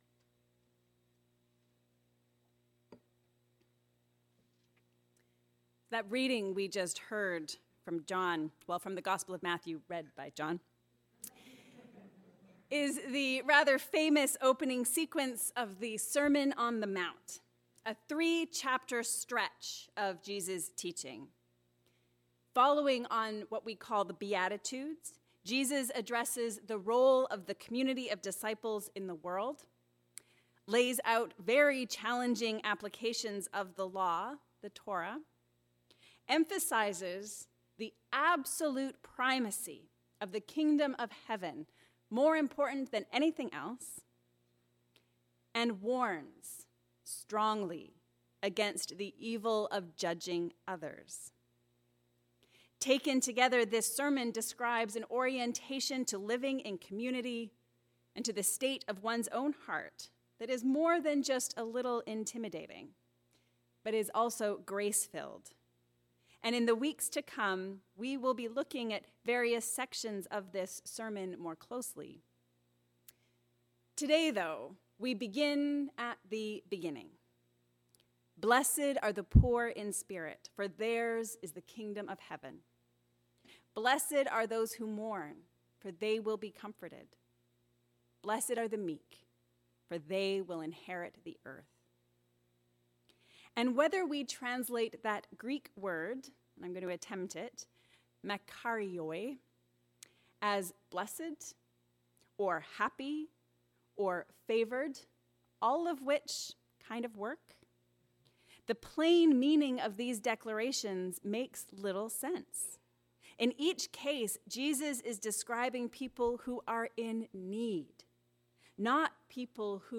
A sermon on Matthew 5:1-12